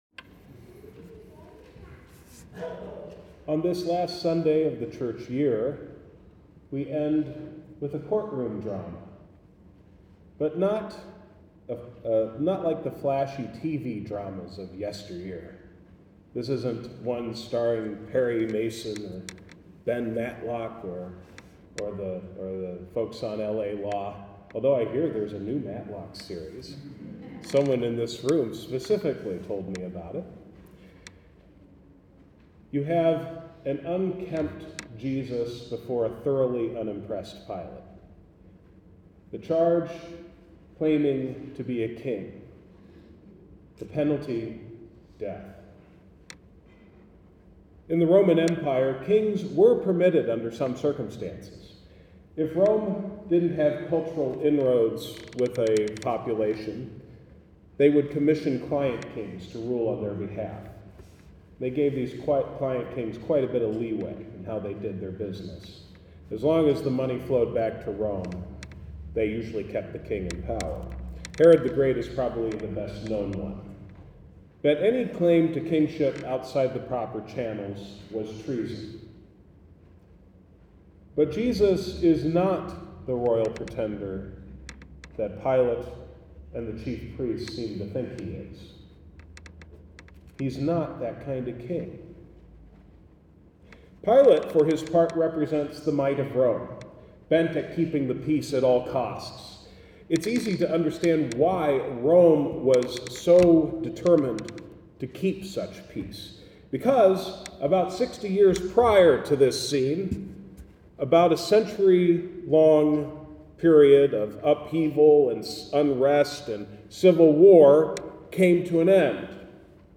Sermons | Shalom Lutheran Church